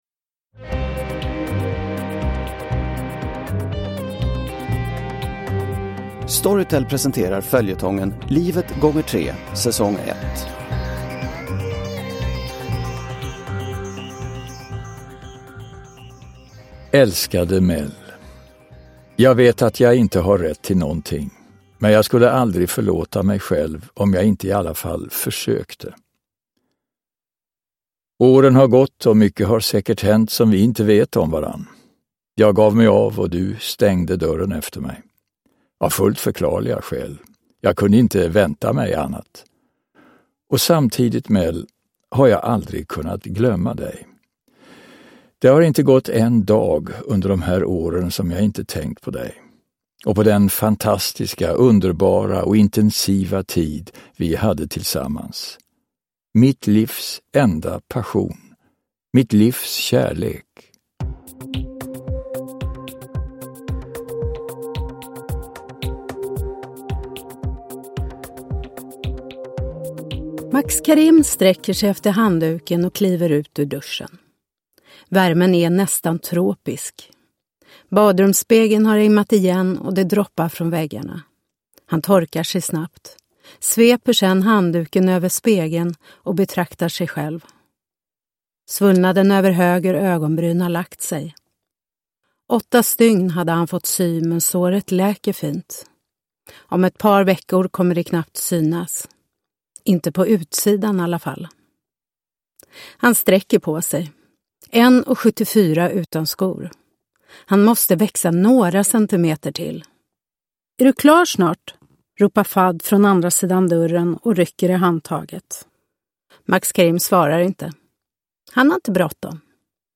Livet x 3 - säsong 1 – Ljudbok – Laddas ner